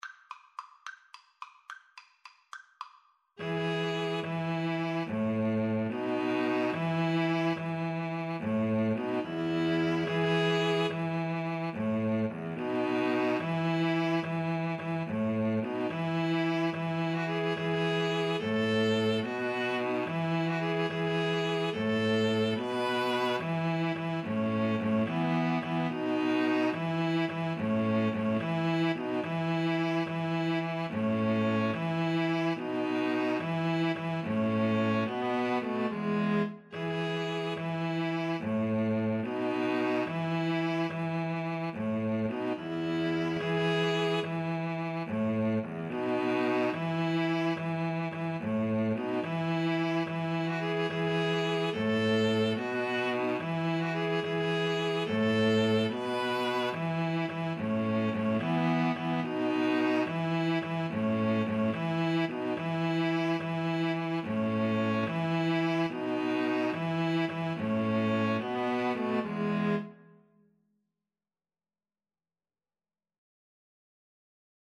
Free Sheet music for String trio
E major (Sounding Pitch) (View more E major Music for String trio )
6/4 (View more 6/4 Music)
Traditional (View more Traditional String trio Music)